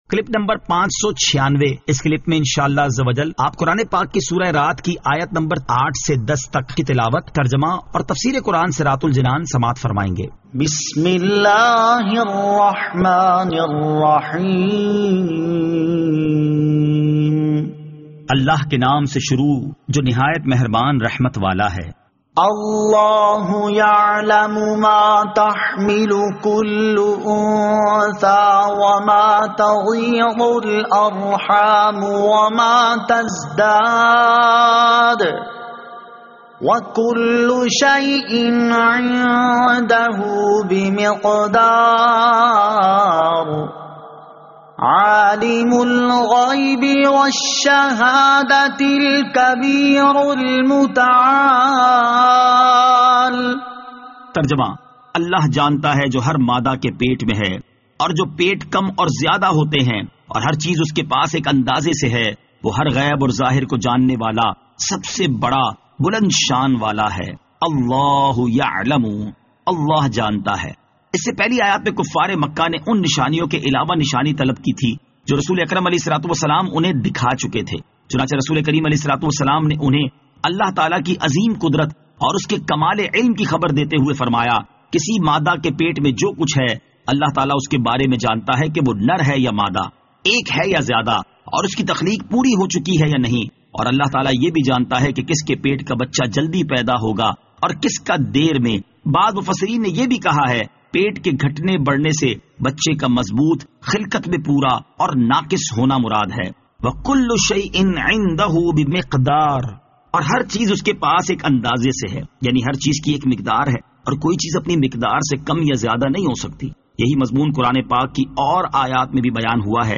Surah Ar-Rad Ayat 08 To 10 Tilawat , Tarjama , Tafseer